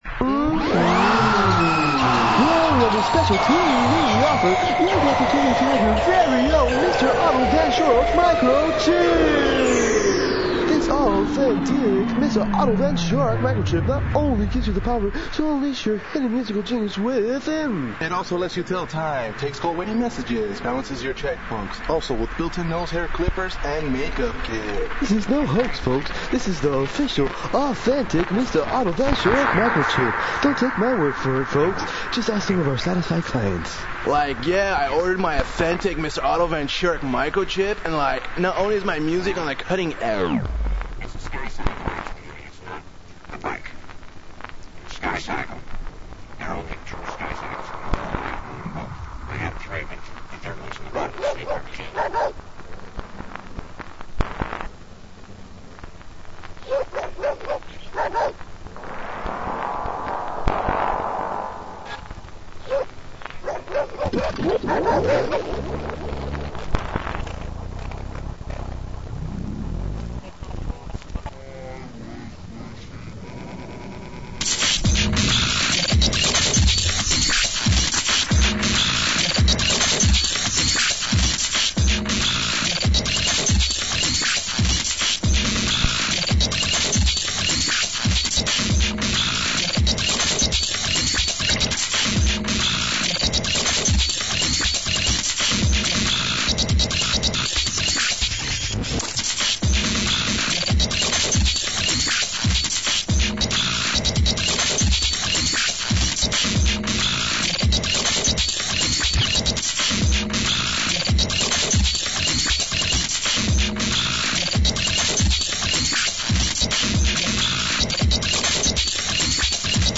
electronica - breakcore Contact